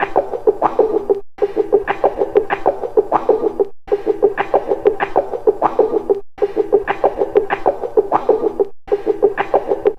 Night Rider - Wah Wah.wav